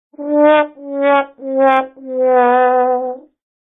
Som De Perdeu
Áudio de perdeu / derrota / mico / vexame.
som-de-perdeu.mp3